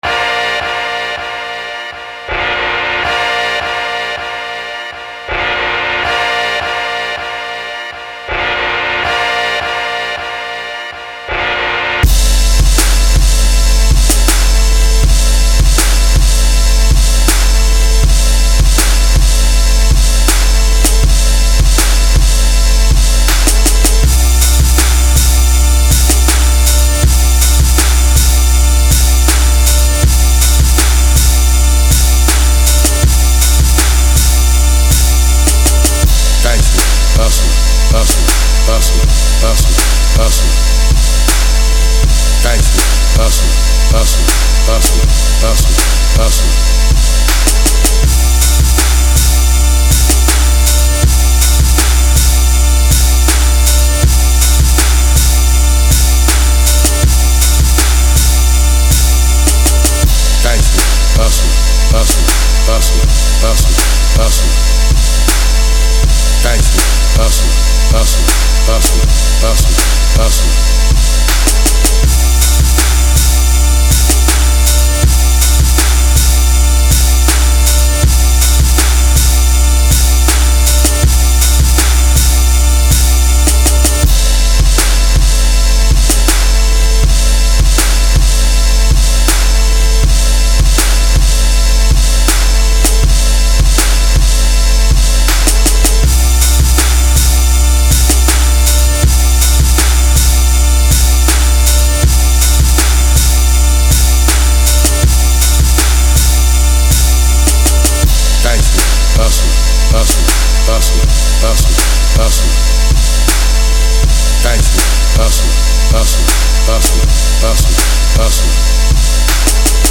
Beats That Make Your Neighbors Come Knocking